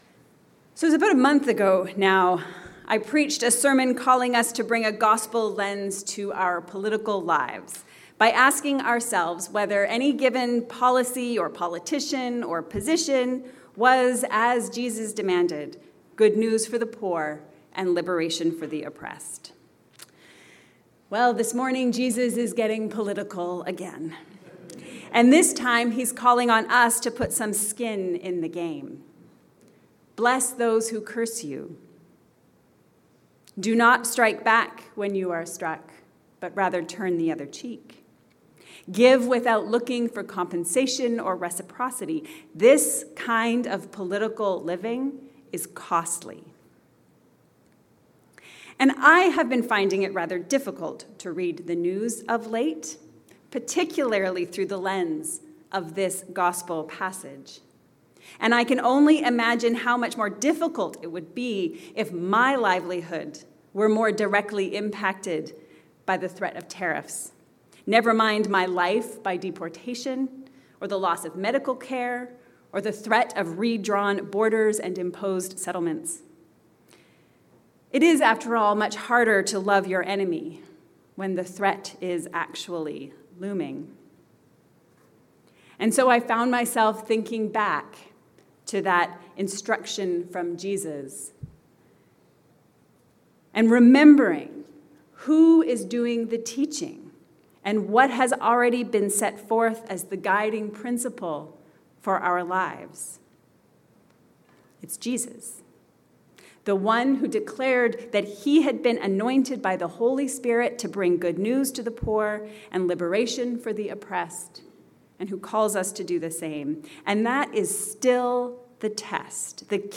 Love as Resistance. A sermon on Luke 6:27-38